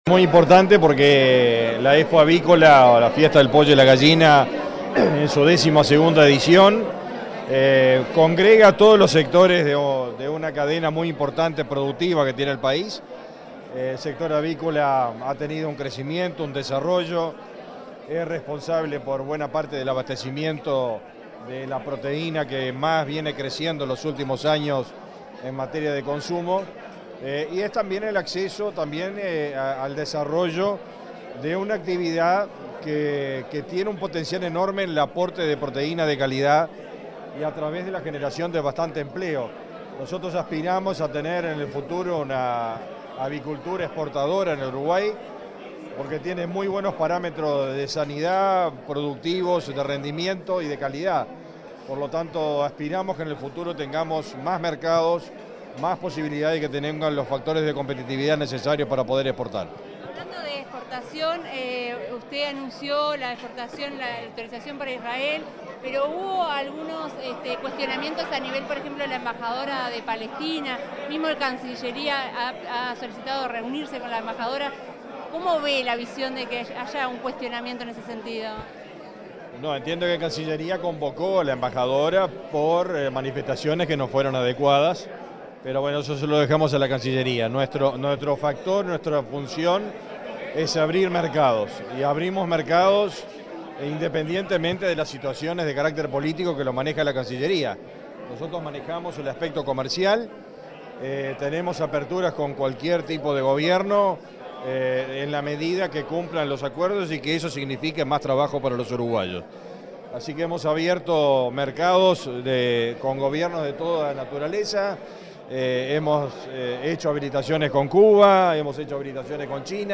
Declaraciones del ministro de Ganadería, Fernando Mattos
Luego Mattos dialogó con la prensa.